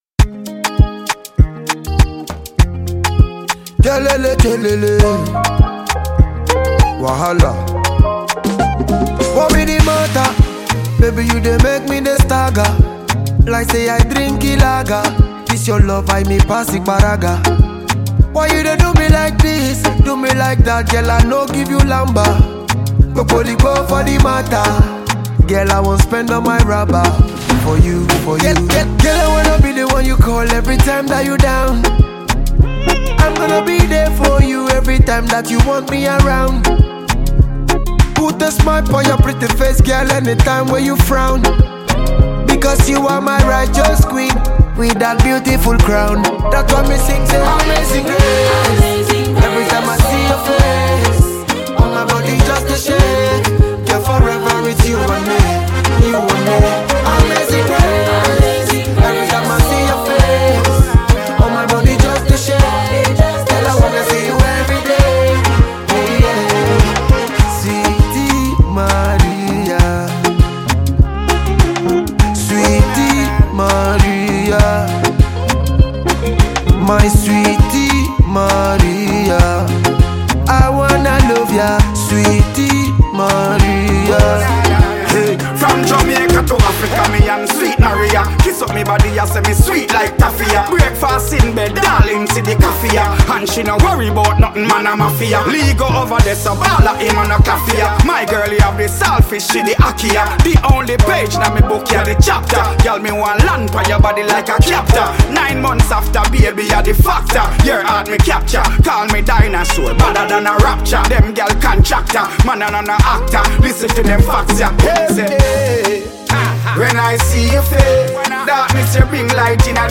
Nigerian dancehall singer and songwriter